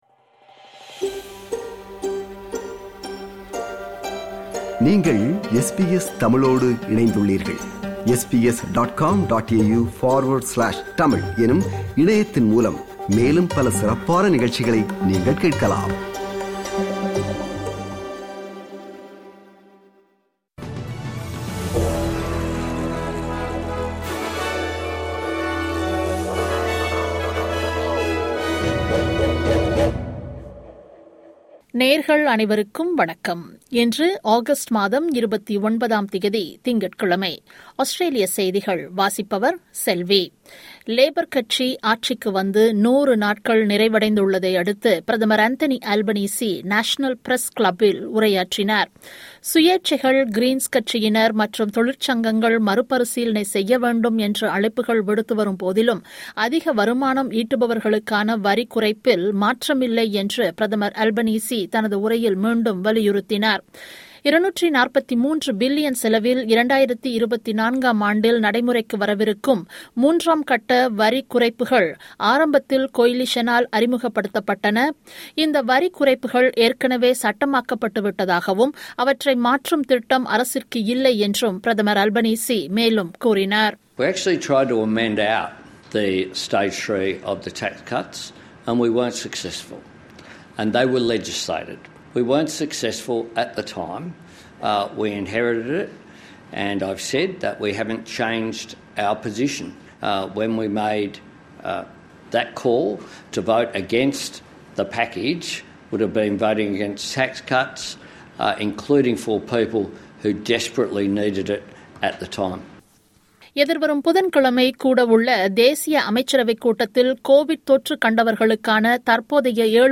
Australian news bulletin for Monday 29 Aug 2022.